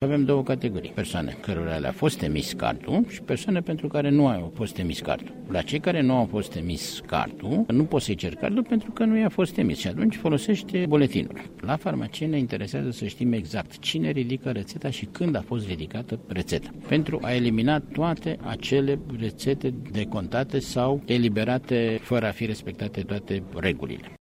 Vasile Ciurchea a explicat şi cum se eliberează reţetele în farmacii: